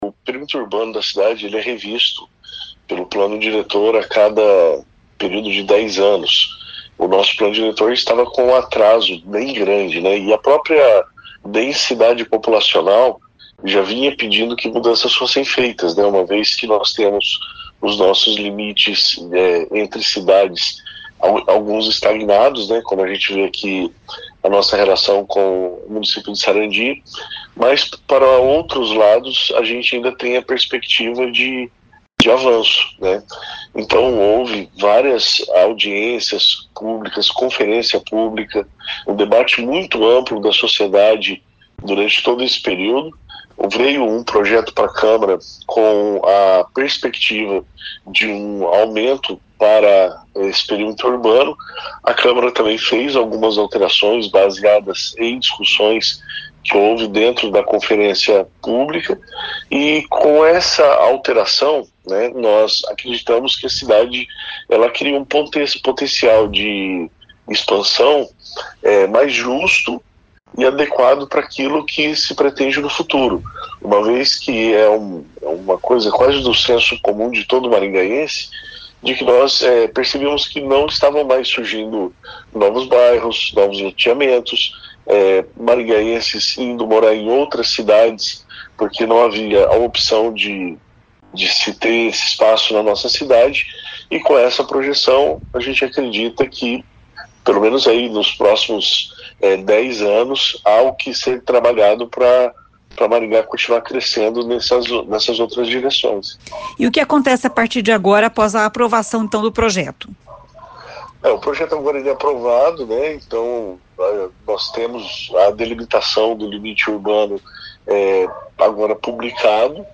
O vereador Alex Chaves, líder do prefeito na Câmara, explica que ainda serão necessárias leis complementares para definir a ocupação do espaço que agora é urbano.